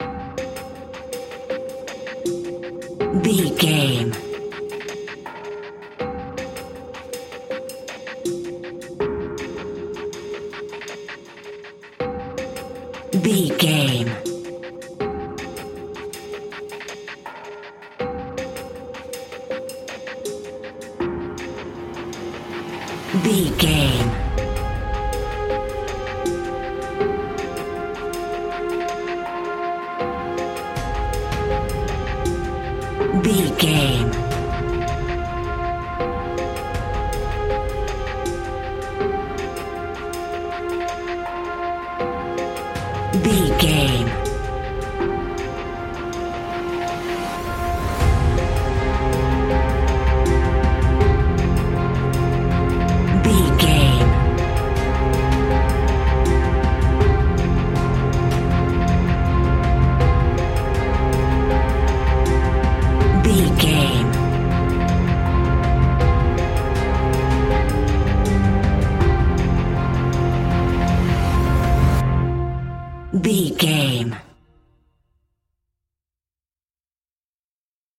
Aeolian/Minor
ominous
dark
eerie
strings
drums
percussion
synthesiser
electric piano
horror music